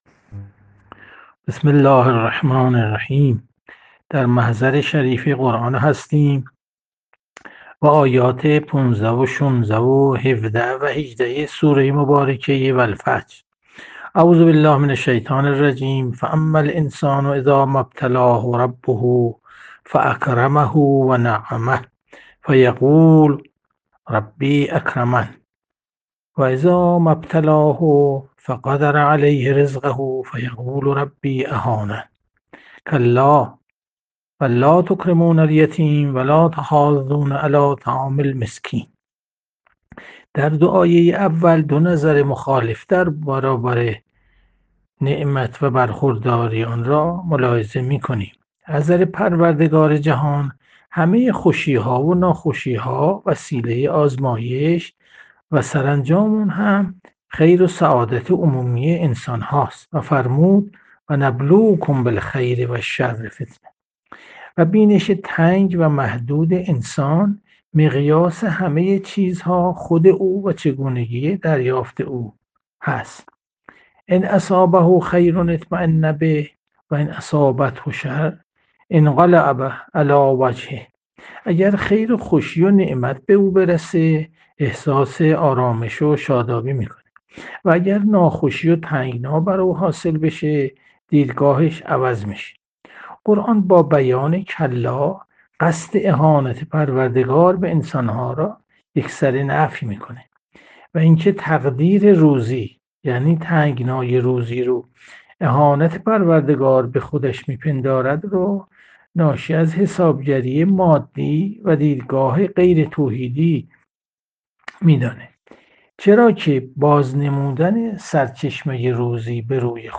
جلسه مجازی هفتگی قرآنی،سوره فجر، 14 آذر 1400